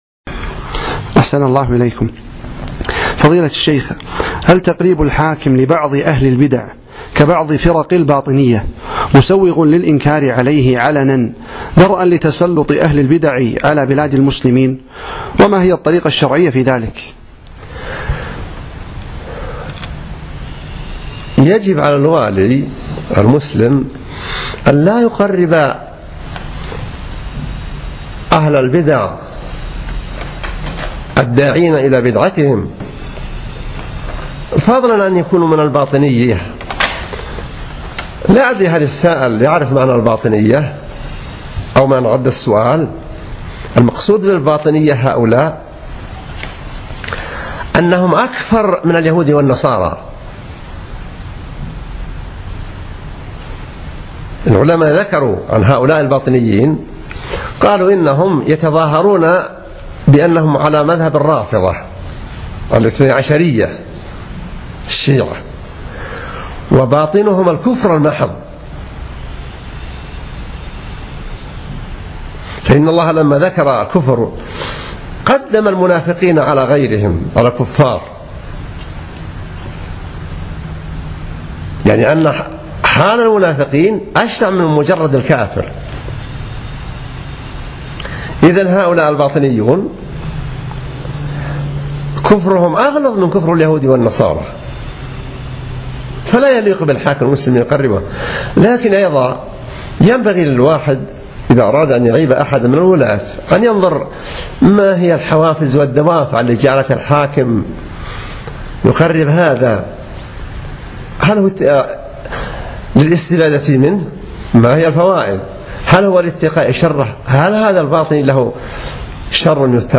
Album: موقع النهج الواضح Length: 2:55 minutes (762.1 KB) Format: MP3 Mono 11kHz 32Kbps (CBR)